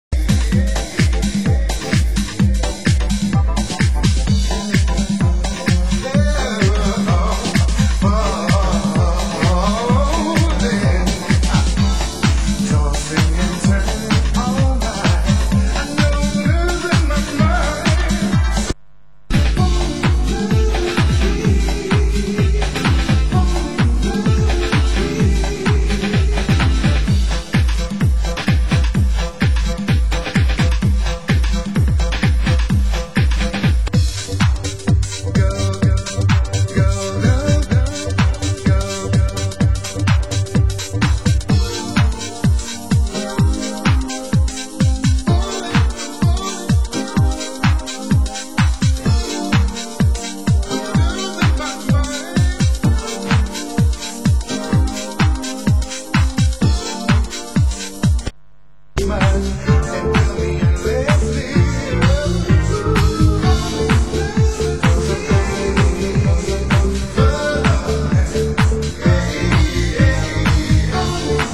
Genre: Deep House
original vocal
deep dark mix